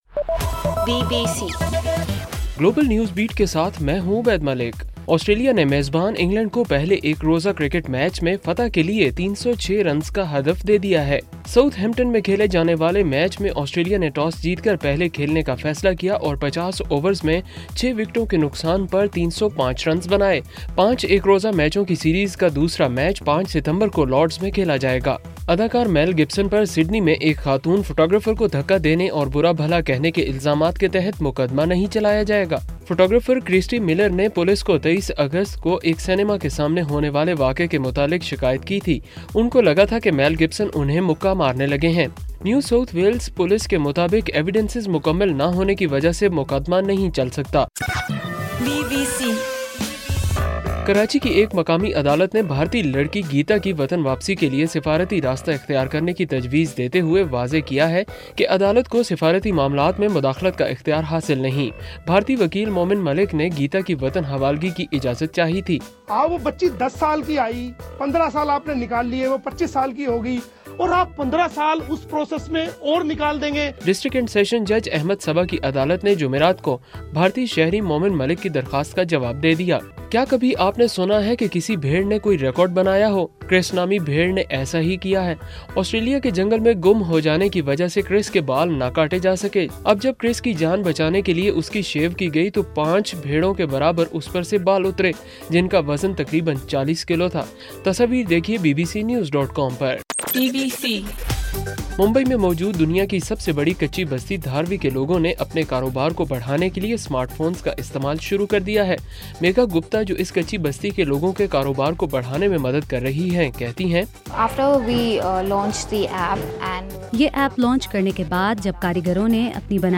ستمبر 3: رات 12 بجے کا گلوبل نیوز بیٹ بُلیٹن